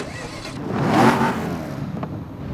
Index of /server/sound/vehicles/lwcars/volvo_s60
startup.wav